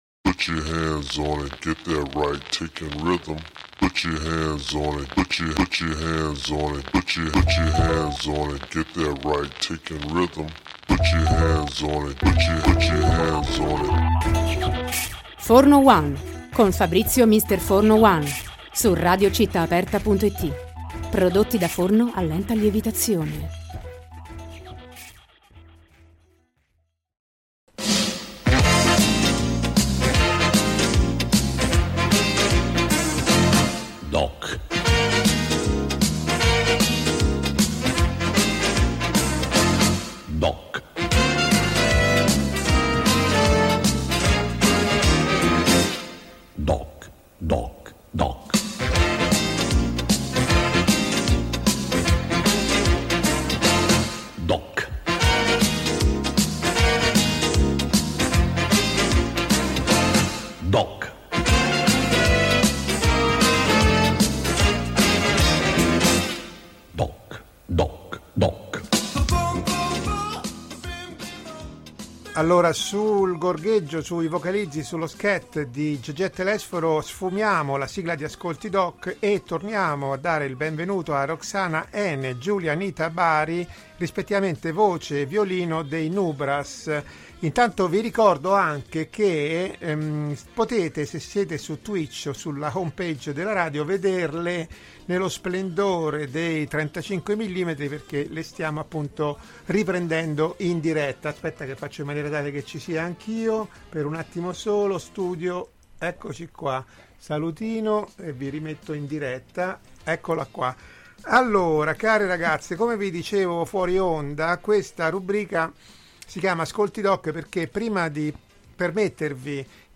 Intervista-Nubras.mp3